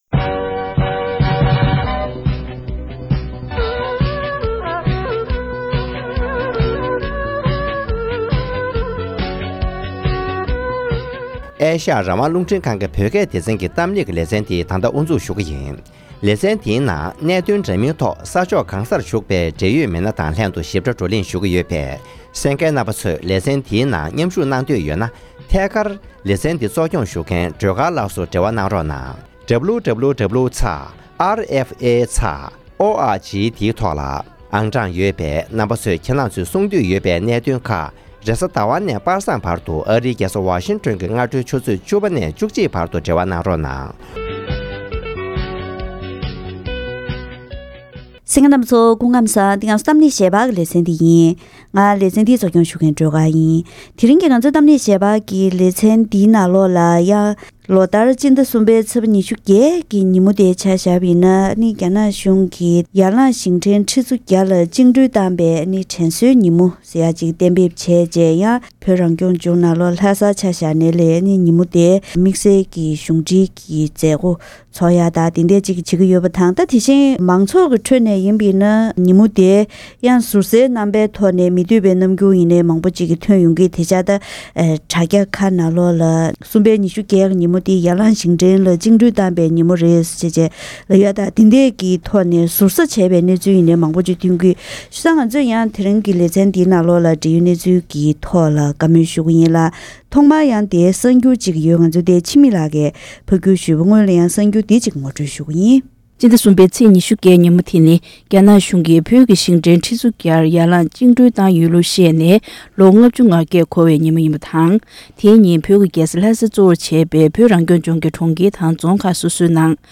༄༅། །ཐེངས་འདིའི་གཏམ་གླེང་ཞལ་པར་གྱི་ལེ་ཚན་ནང་།